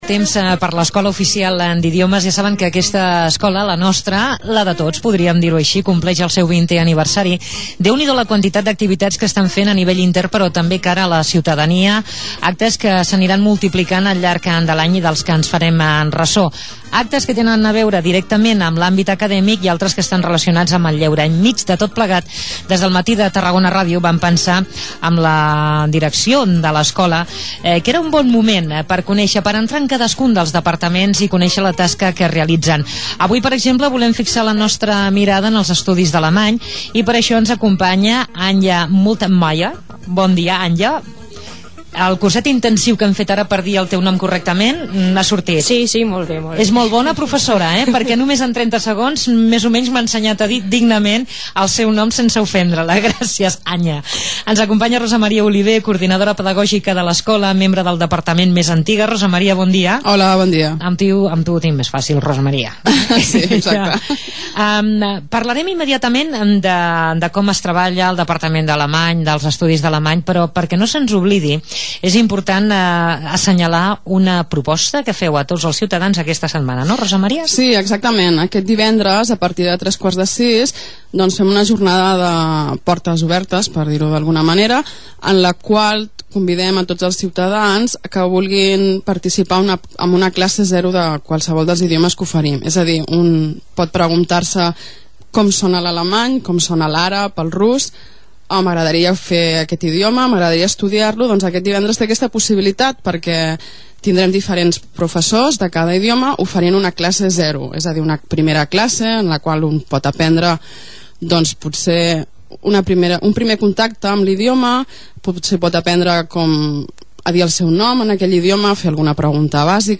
Rund um Deutschland Deutschlandpuzzle 2 Deutschlehrerinnen aus der EOI-TGNA wurden im Radio interviewt.
entrevista.mp3